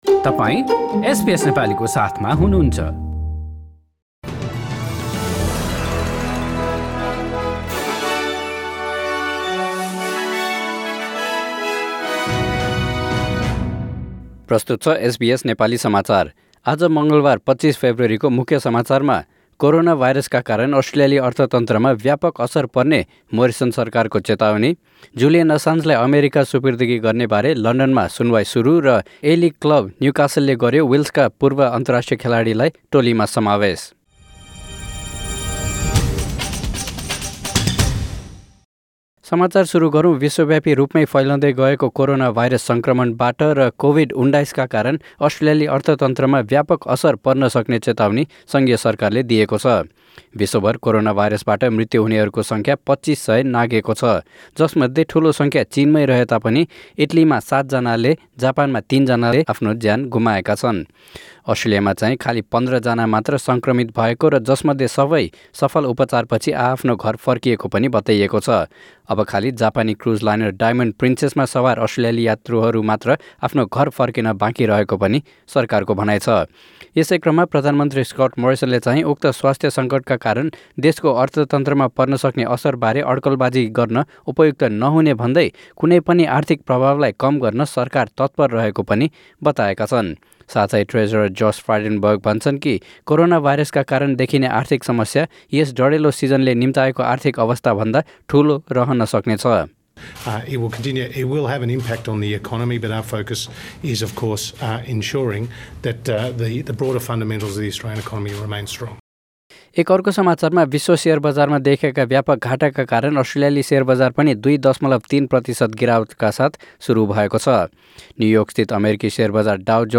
एसबीएस नेपाली अस्ट्रेलिया समाचार: मङ्गलवार २५ फेब्रुअरी २०२०